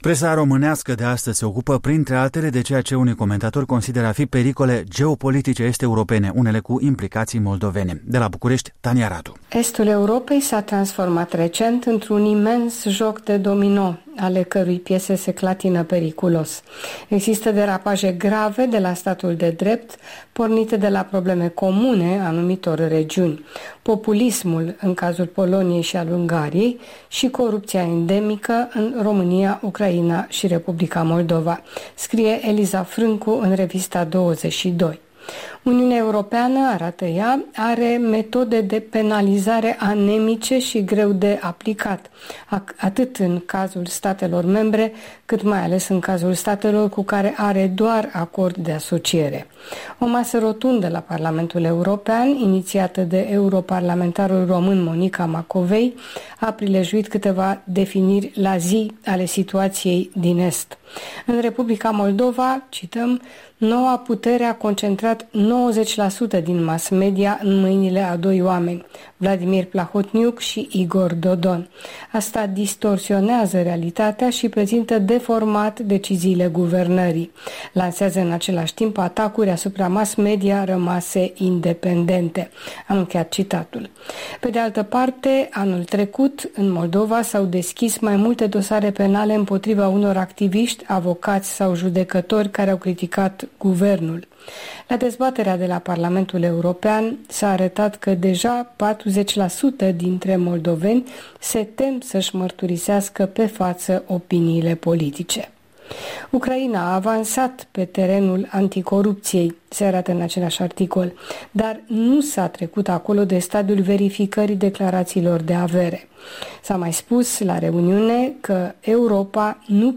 Revista presei bucureștene